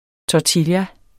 Udtale [ tɒˈtilja ]